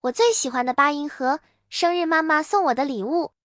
我最喜欢的八音盒，生日妈妈送我的礼物.MP3